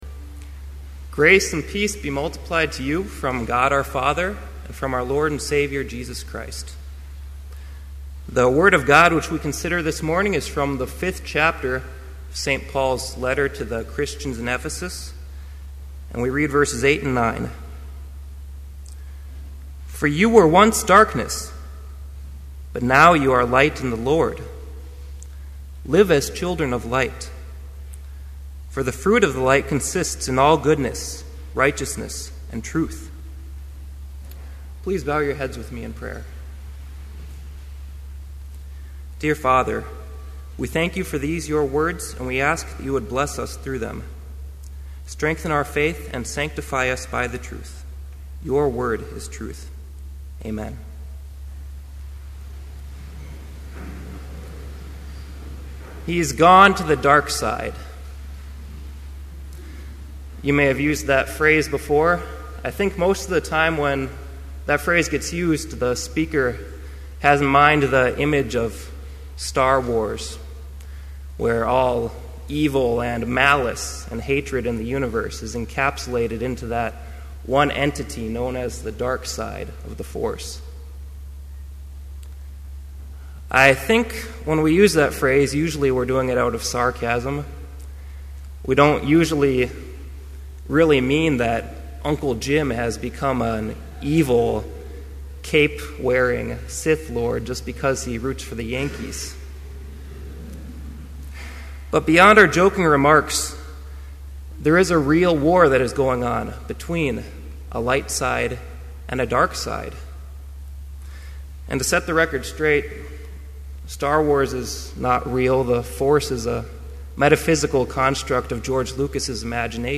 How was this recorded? This Chapel Service was held in Trinity Chapel at Bethany Lutheran College on Tuesday, March 13, 2012, at 10 a.m. Page and hymn numbers are from the Evangelical Lutheran Hymnary.